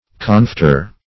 Search Result for " confiteor" : The Collaborative International Dictionary of English v.0.48: Confiteor \Con*fit"e*or\, n. [L., I confess.